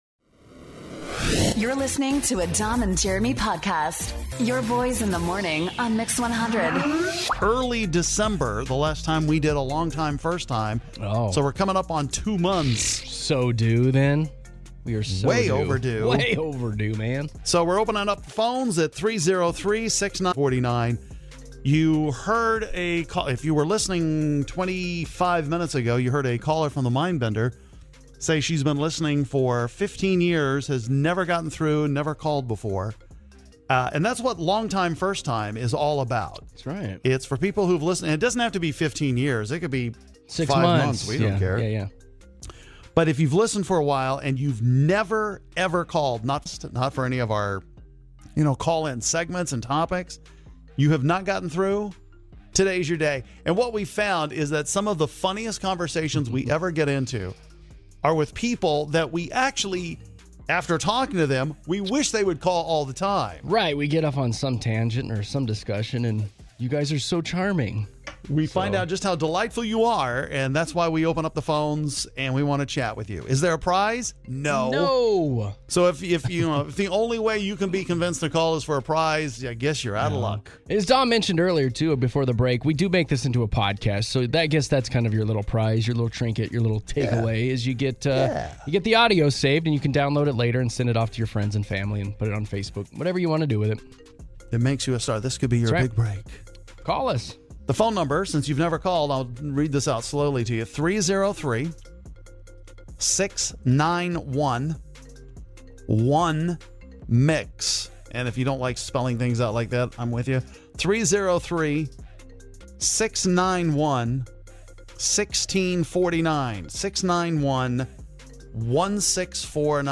We talk to some long time listeners who are first time callers!